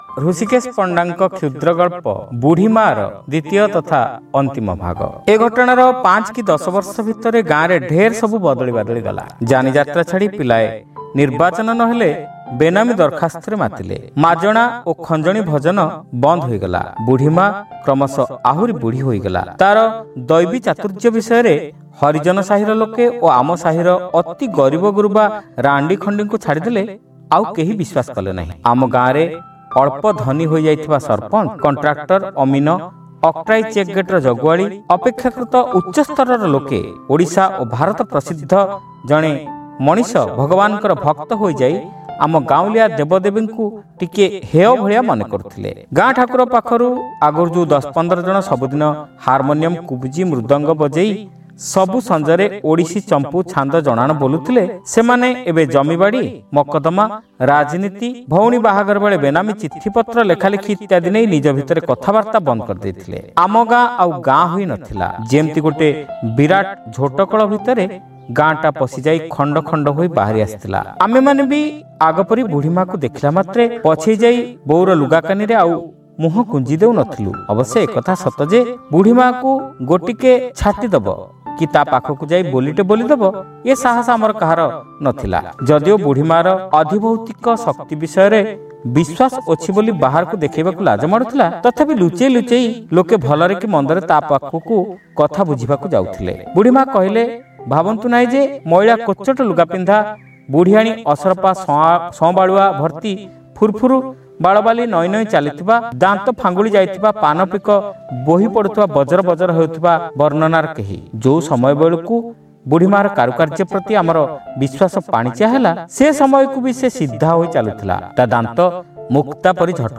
ଶ୍ରାବ୍ୟ ଗଳ୍ପ : ବୁଢ଼ୀ ମାଆ (ଦ୍ୱିତୀୟ ଭାଗ)